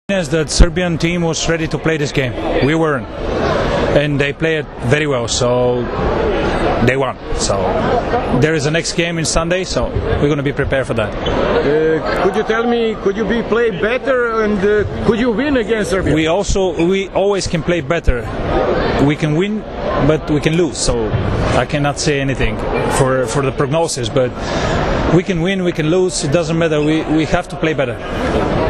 IZJAVA TODORA ALEKSIJEVA